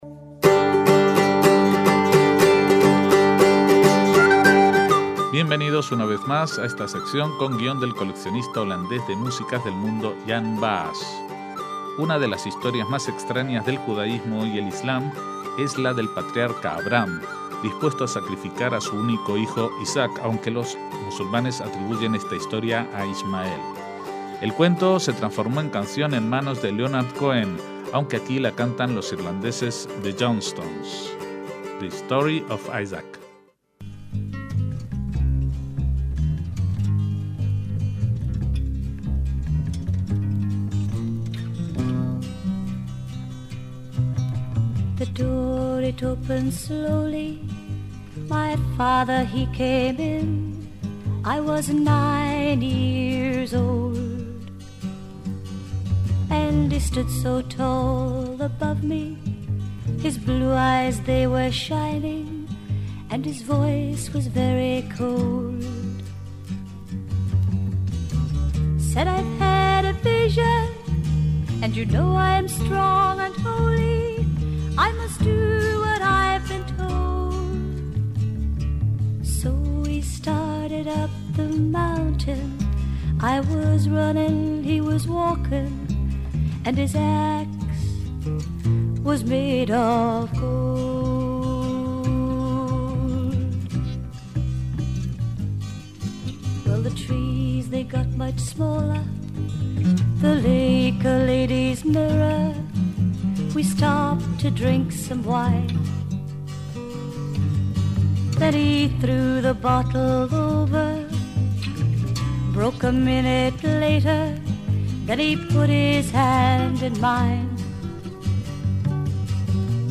El episodio bíblico del afortunadamente interrumpido sacrificio de Isaac a manos de su padre Abraham ha sido cantado en muchas lenguas, algunas de las cuales escuchamos en esta entrega.